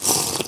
drink.mp3